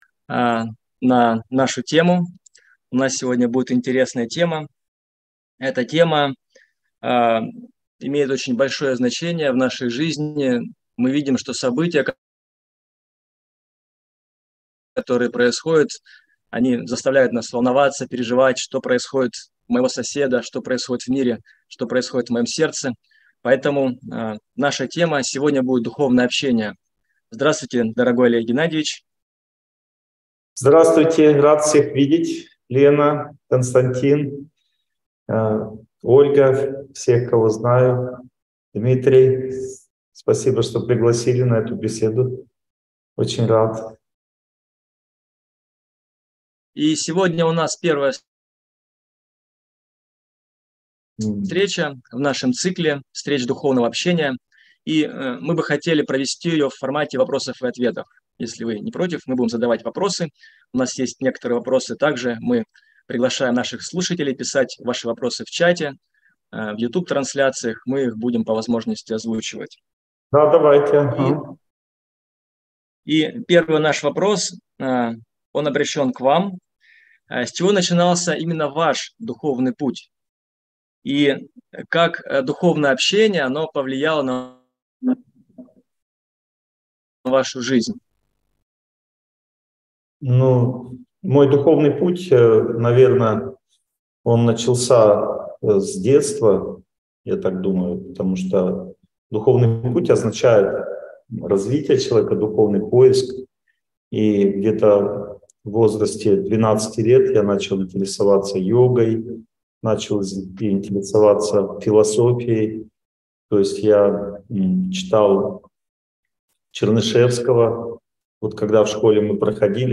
Общение на духовные темы (вебинар, 2023)